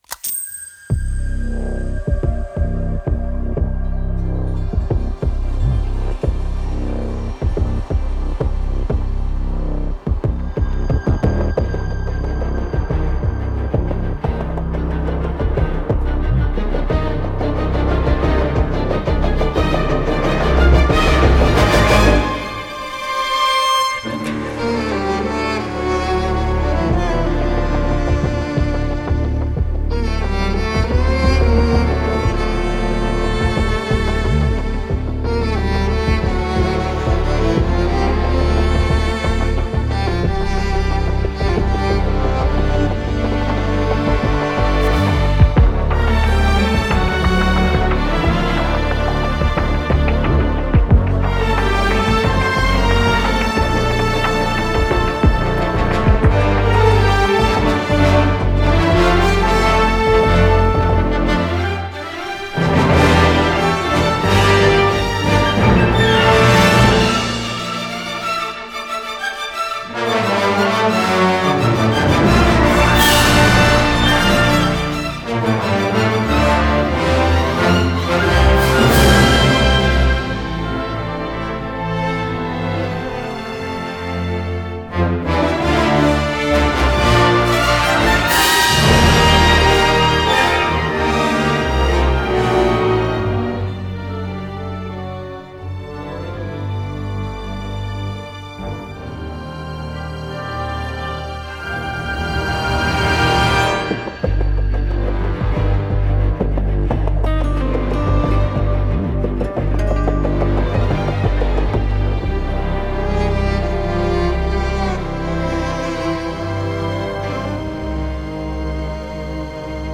très agréable, coloré, jamais lourdingue.
Pas mal quand le score se fait intimiste d’ailleurs.